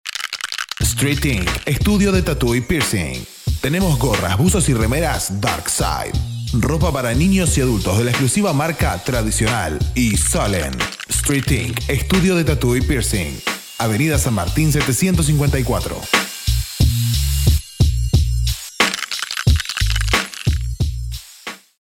locutor argentino voz juvenil y muy versatil de tono medio a grave
Sprechprobe: Industrie (Muttersprache):